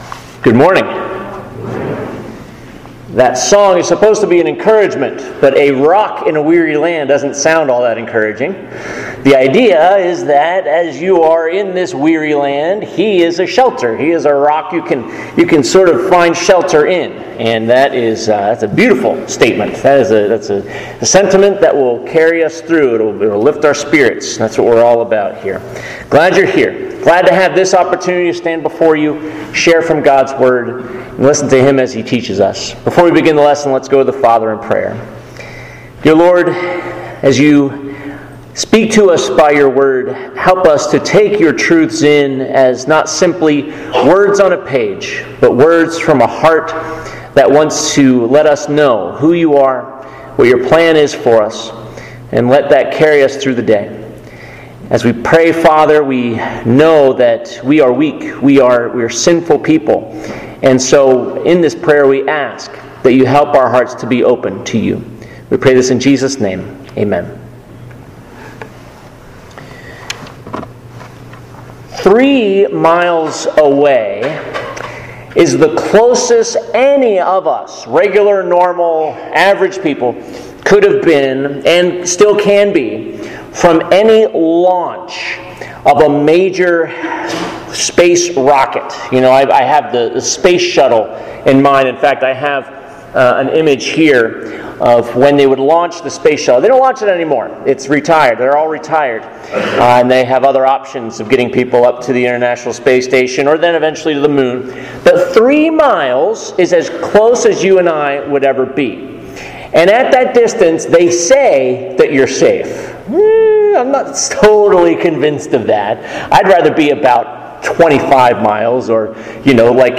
Ignited — Sermon Series